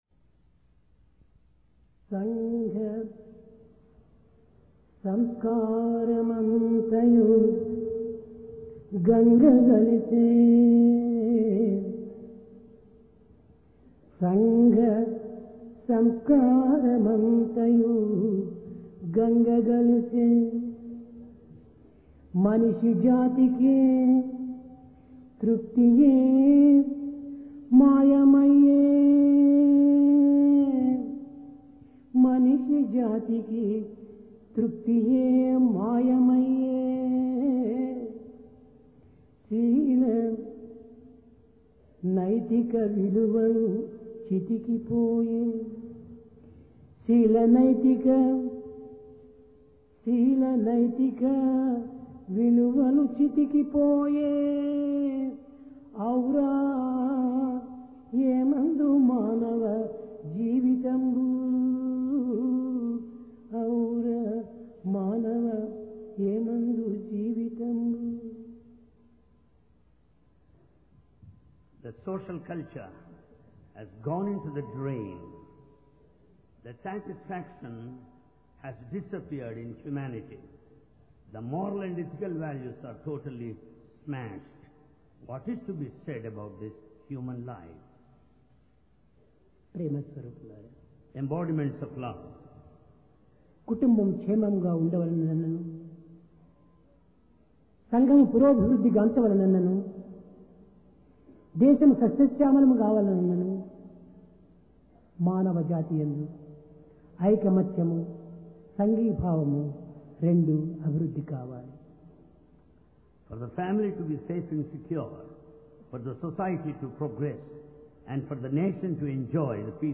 22 Jun 1996 Occasion: Divine Discourse Place: Prashanti Nilayam Quest For AtmaJnana For the progress of a family or a society or a nation